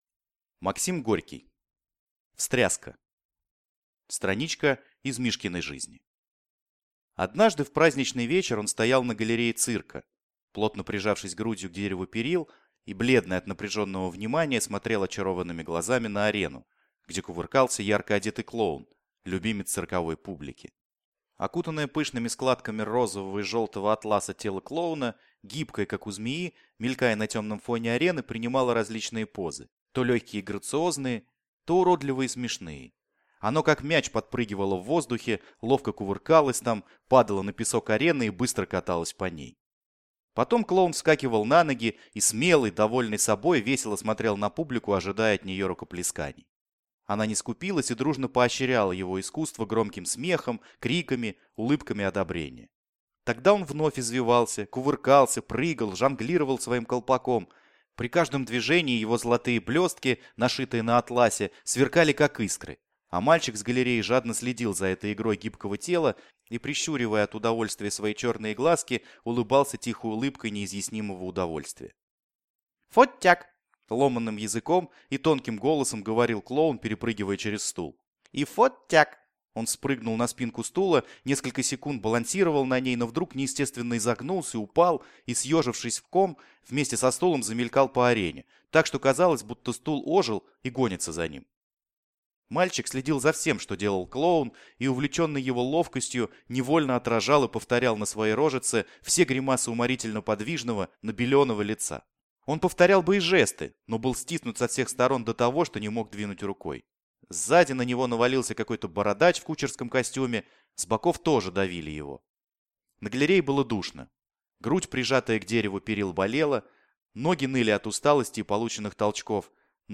Аудиокнига «Встряска» | Библиотека аудиокниг